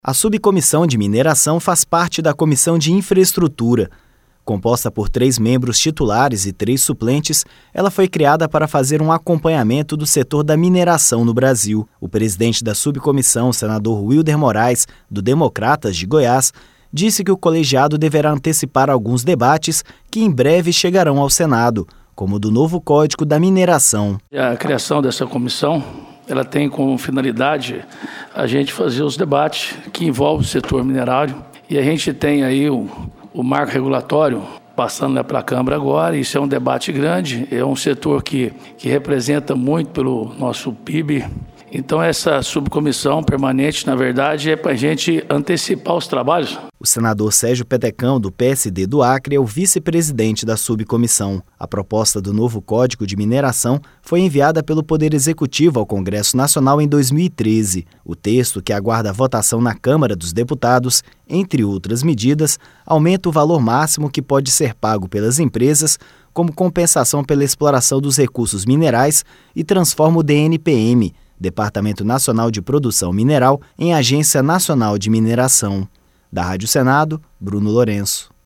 Para o presidente da Subcomissão, senador Wilder Morais (DEM-GO), o colegiado irá antecipar o debate no Senado, considerando que a mineração é um dos setores da economia que contribui muito para o PIB nacional.